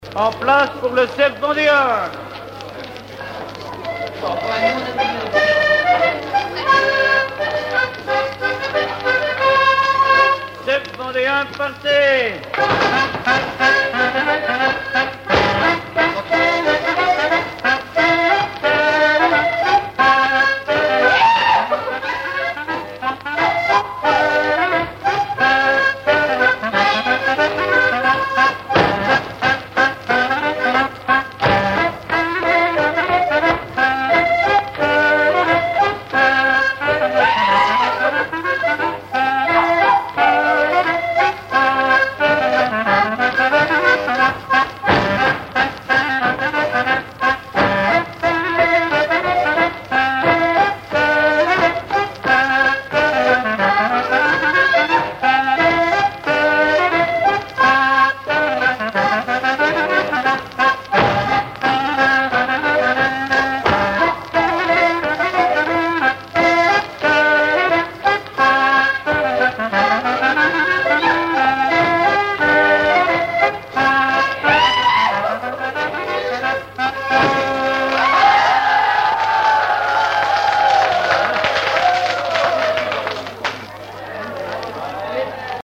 Mémoires et Patrimoines vivants - RaddO est une base de données d'archives iconographiques et sonores.
danse : scottich sept pas
Pièce musicale inédite